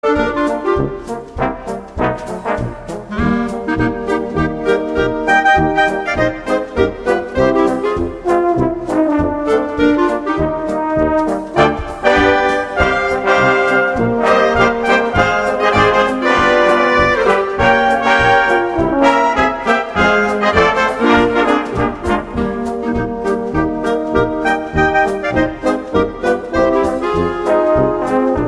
Besetzung: Blasorchester
Foxtrott
Tonart: Es + F-Dur